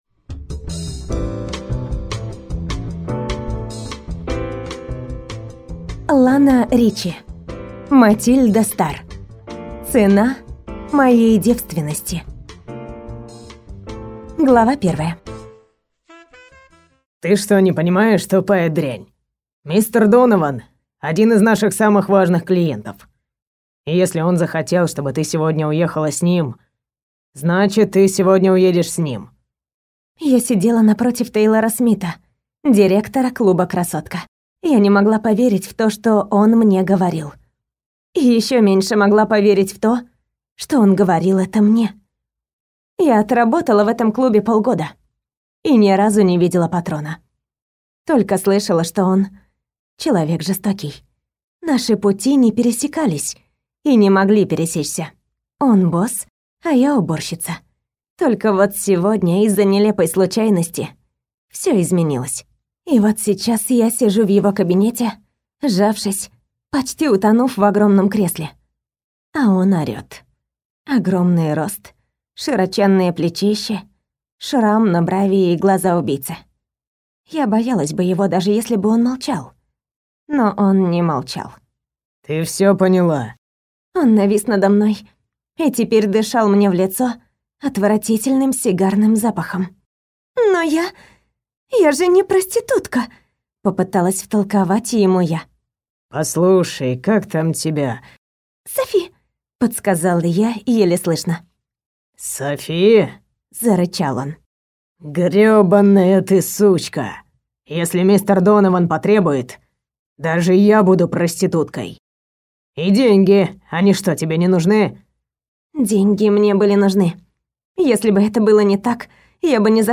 Аудиокнига Цена моей девственности | Библиотека аудиокниг